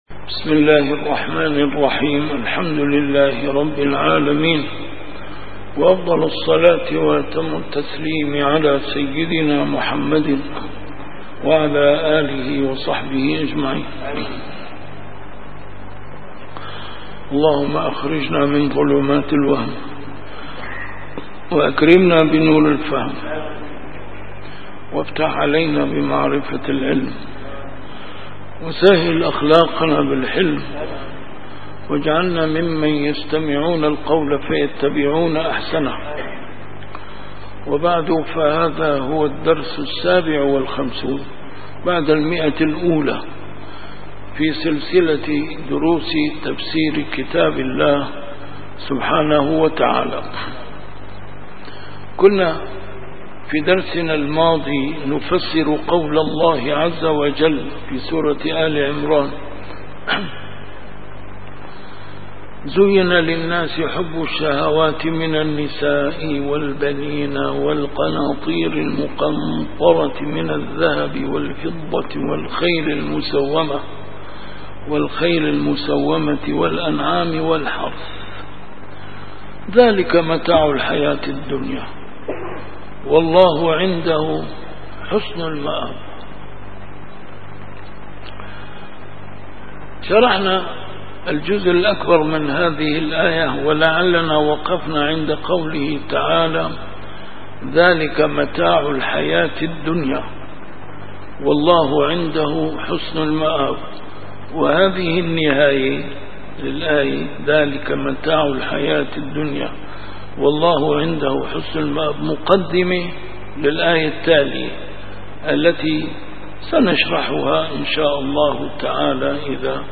A MARTYR SCHOLAR: IMAM MUHAMMAD SAEED RAMADAN AL-BOUTI - الدروس العلمية - تفسير القرآن الكريم - تفسير القرآن الكريم / الدرس السابع والخمسون بعد المائة: سورة آل عمران: الآية 14 - 15